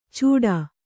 ♪ cūḍā